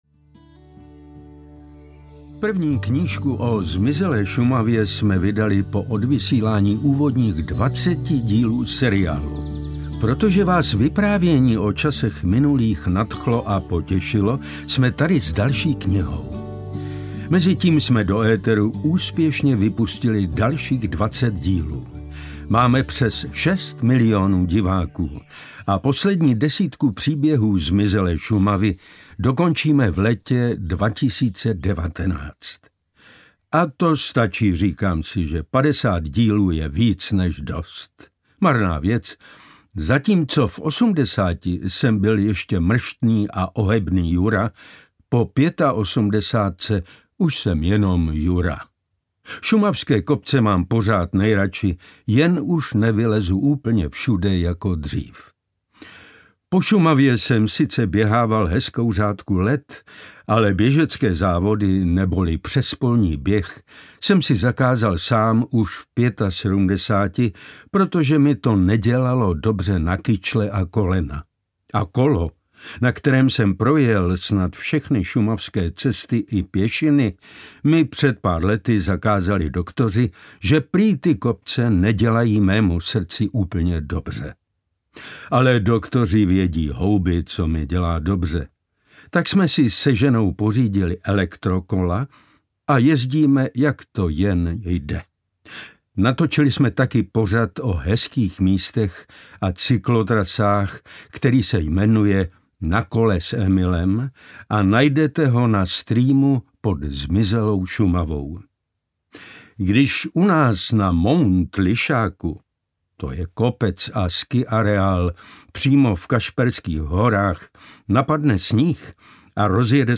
Zmizelá Šumava 2 audiokniha
Ukázka z knihy
• InterpretPetr Štěpánek